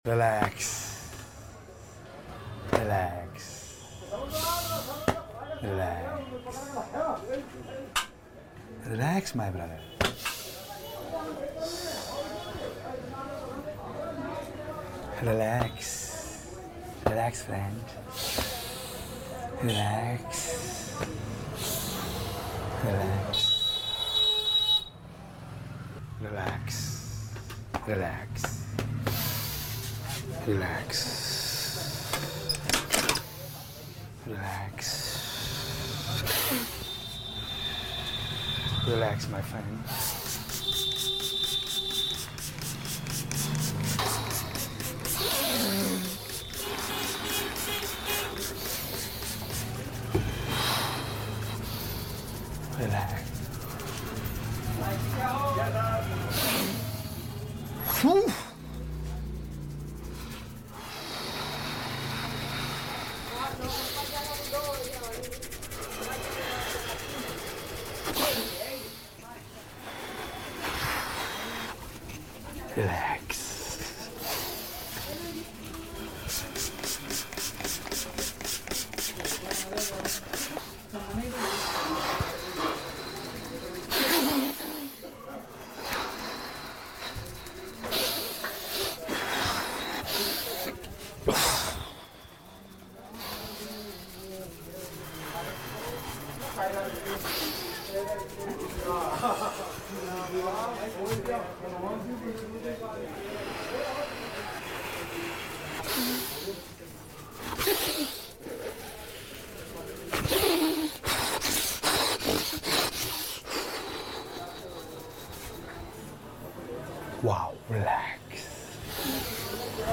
$1 Relaxing ASMR Indian Head Sound Effects Free Download